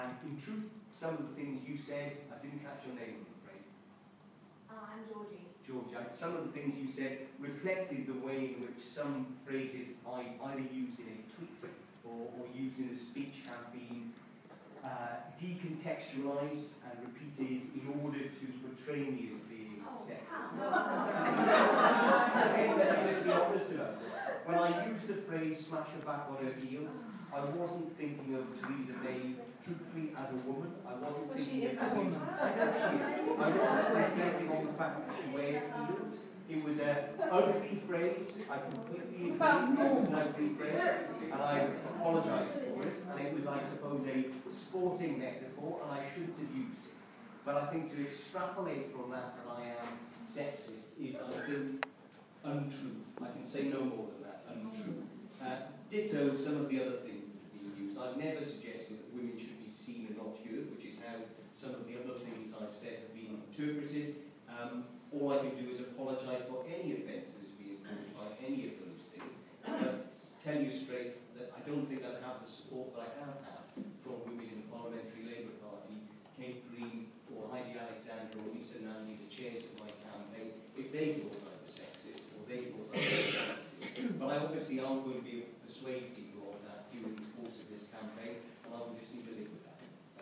Owen Smith jeered and heckled for defending 'sexist' comments
The Labour leadership-hopeful was tackled at a women's hustings in London